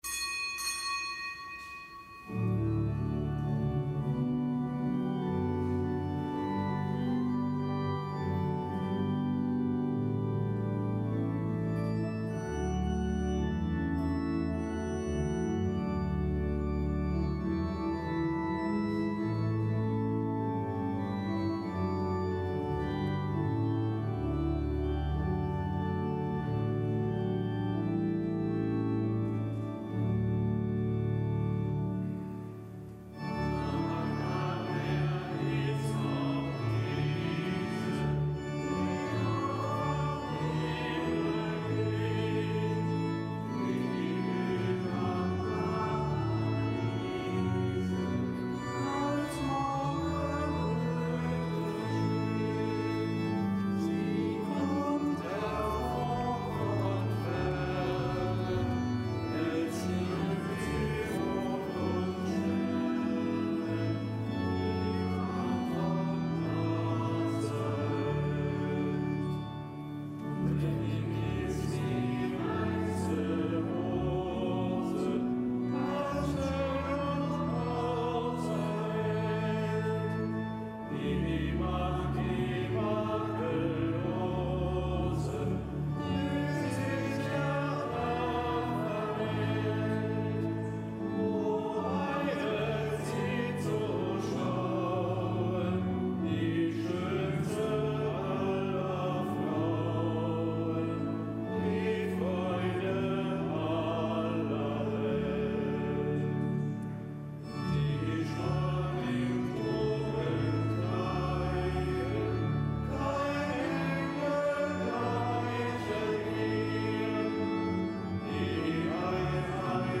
Kapitelsmesse aus dem Kölner Dom am Fest Mariä Geburt.